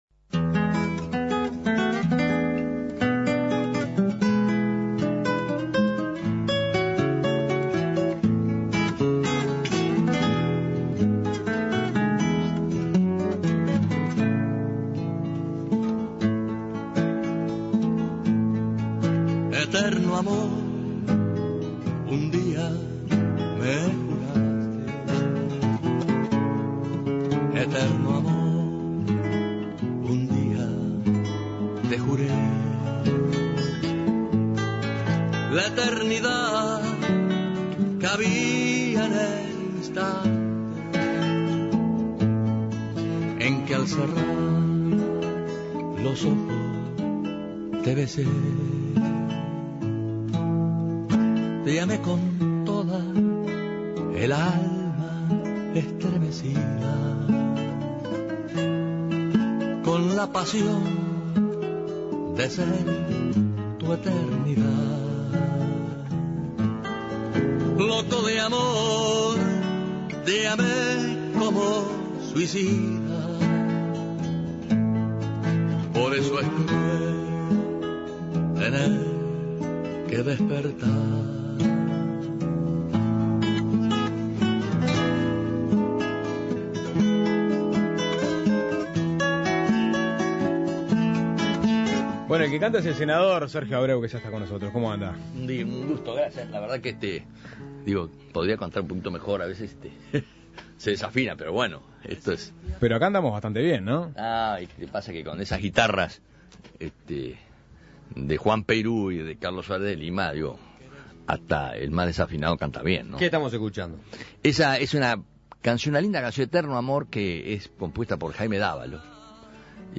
Para analizar el momento que atraviesa el Partido Nacional, y los planes que tiene para esta nueva agrupación, Dignidad Nacional, Suena Tremendo recibió al Dr. Sergio Abreu.